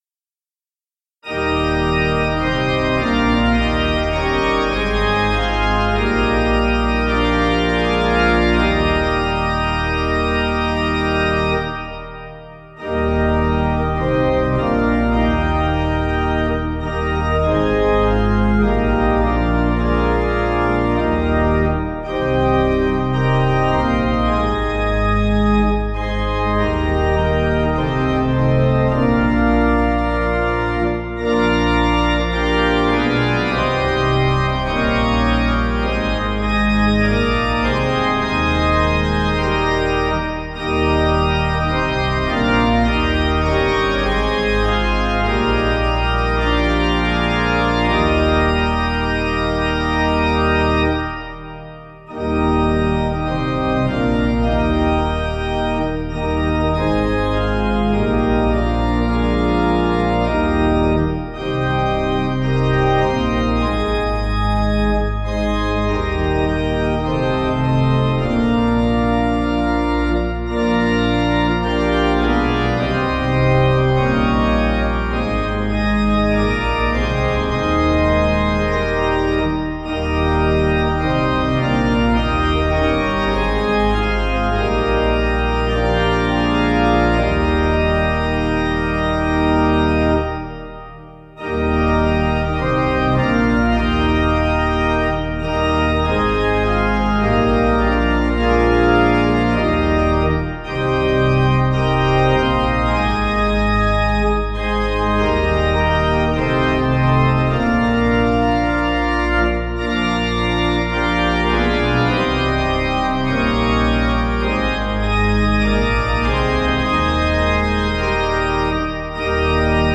Organ
(CM)   3/Eb 466kb